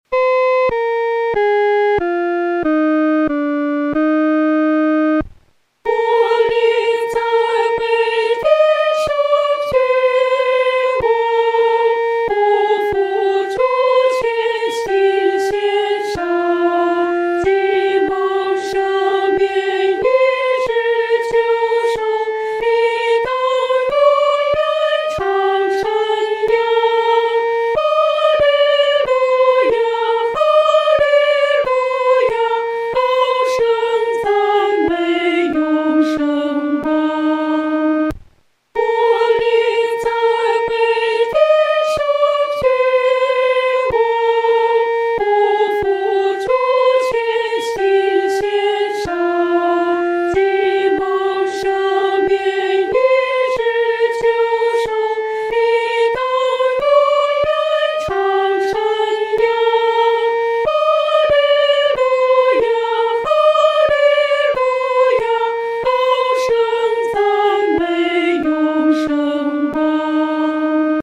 女高
本首圣诗由网上圣诗班 (南京）录制